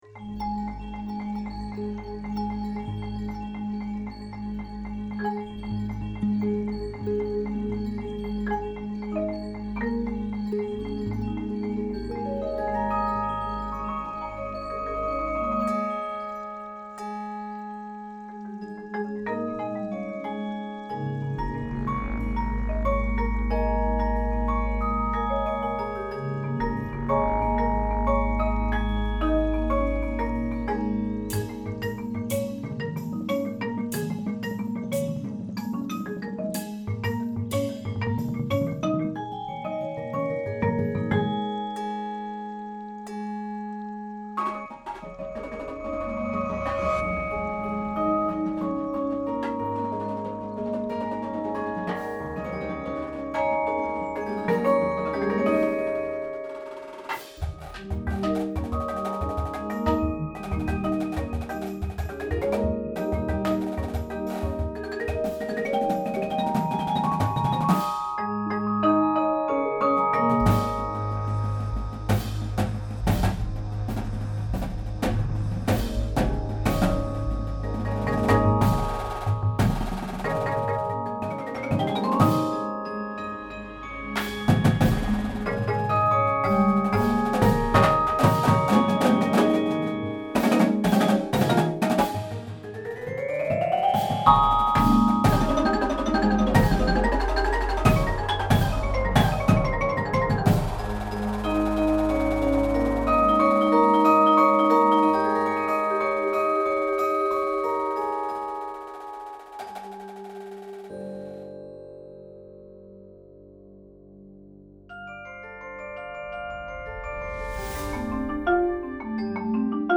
• Marching Snare Drum
• Marching Tenors (4, 5, and 6 drum parts available)
• Marching Bass Drum (3, 4, and 5 drum parts available)
• Marching Cymbals
Front Ensemble
• Synthesizer (Mainstage patches included)
• Marimba 1/2
• Glockenspiel/Xylophone
• Vibraphone 1/2